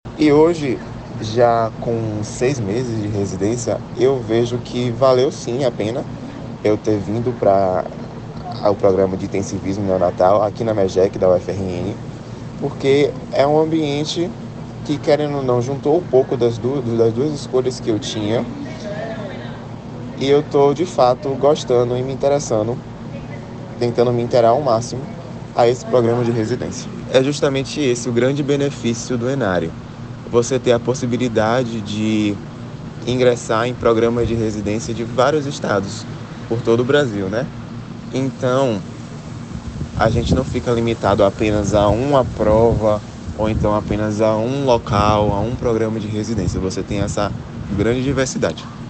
Relato de quem já fez